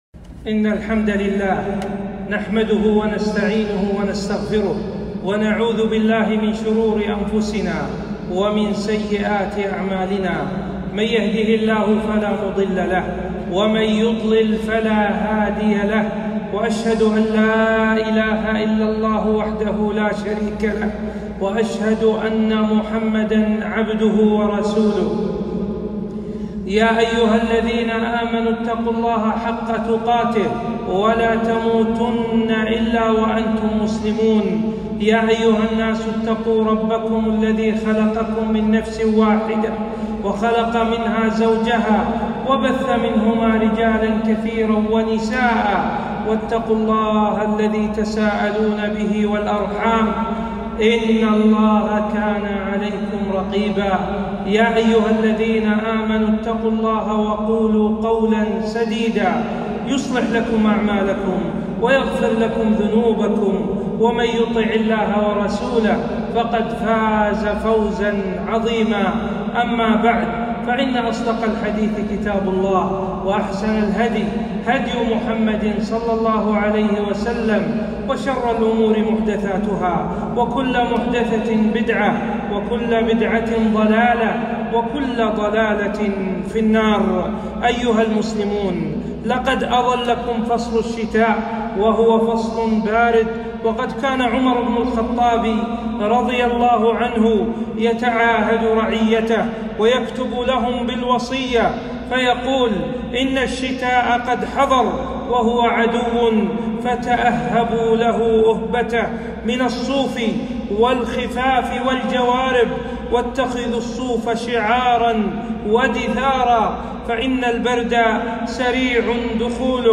خطبة - أحكام الشتاء